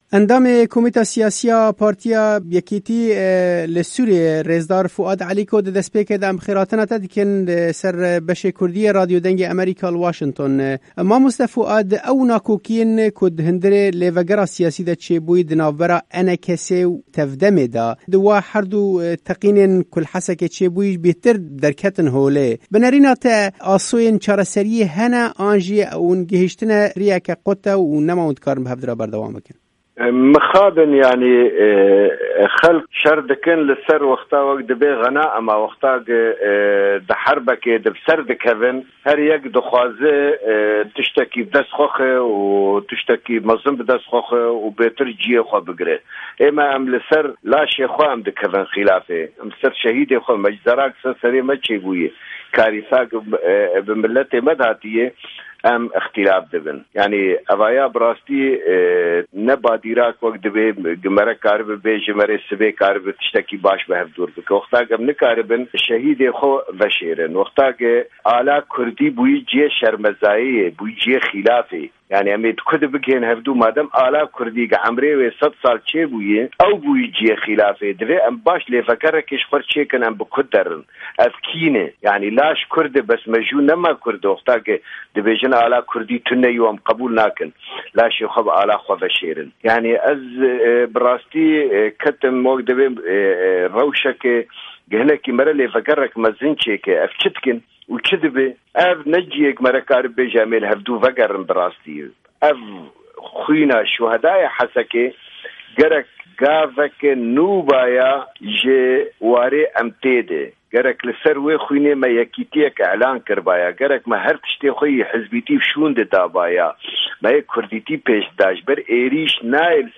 Endamê Komîta Siyasî ya Partiya Yekîtî Kurdî li Sûriyê, rêzdar Fuad Elîko, di hevpeyvînekê de ligel Dengê Amerîka şîrove dike.